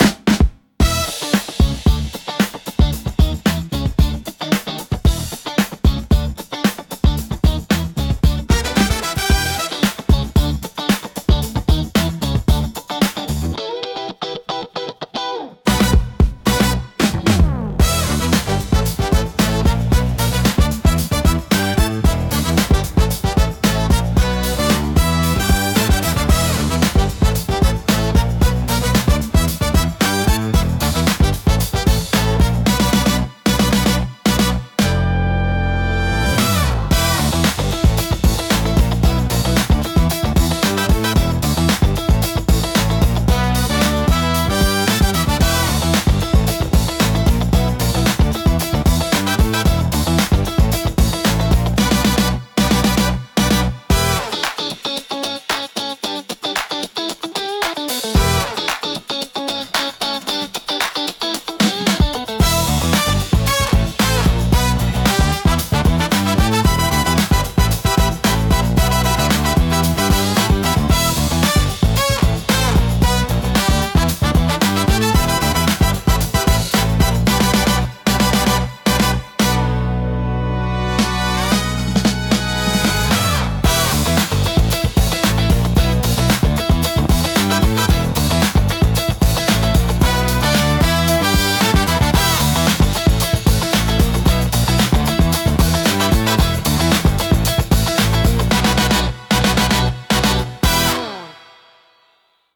心地よいリズムが聴く人を踊らせ、楽しい雰囲気を盛り上げる効果があります。躍動感と活気に満ちたジャンルです。